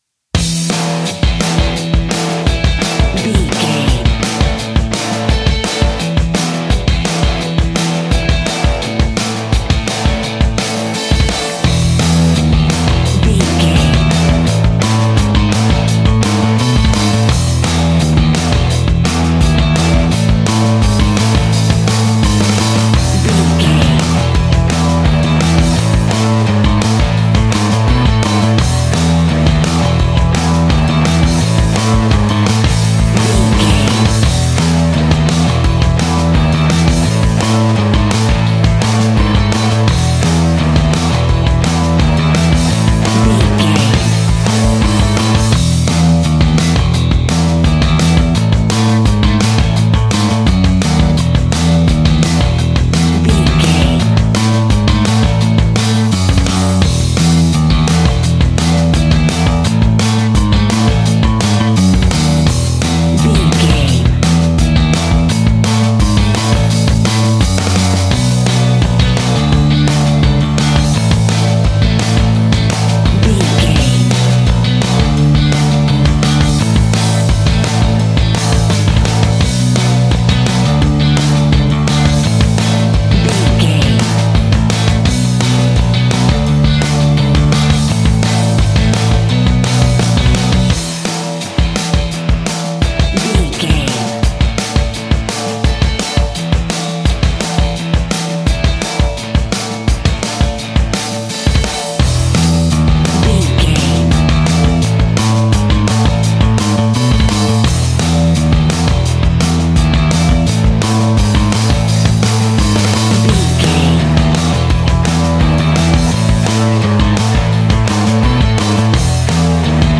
Mixolydian
fun
energetic
uplifting
instrumentals
upbeat
rocking
groovy
guitars
bass
drums
piano
organ